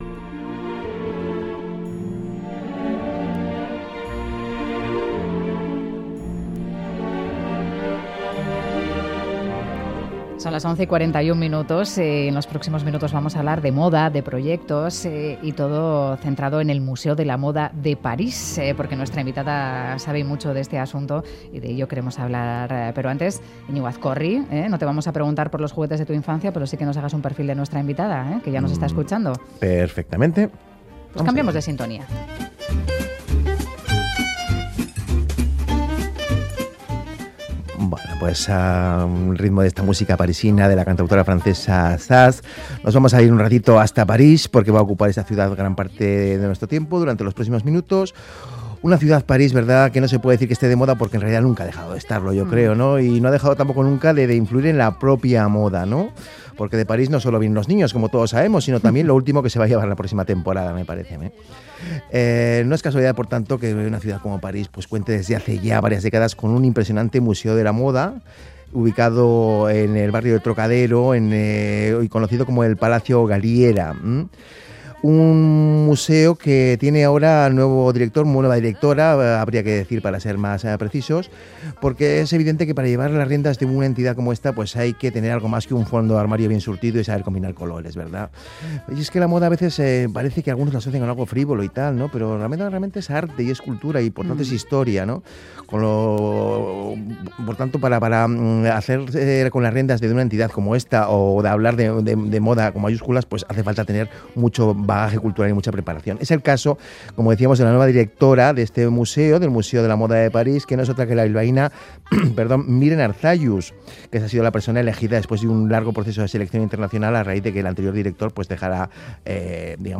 Charlamos de moda, de historia y de arte